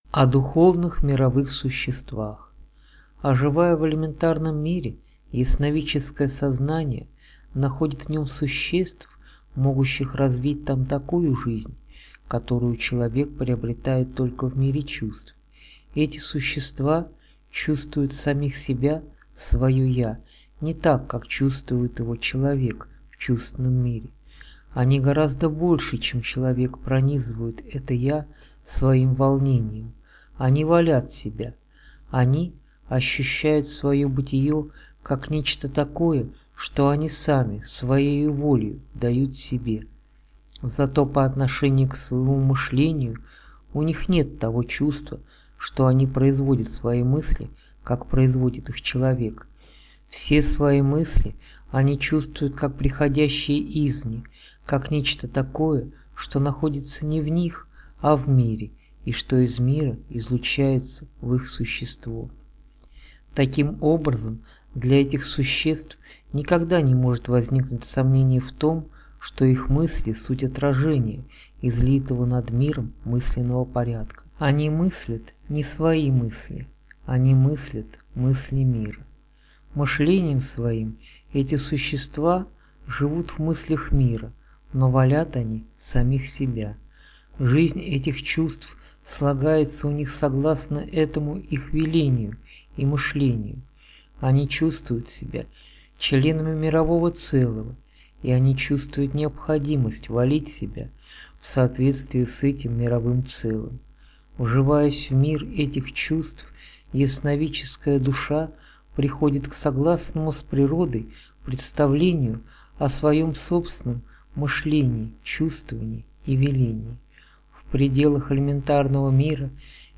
Автор Рудольф Штайнер из аудиокниги "Порог духовного мира".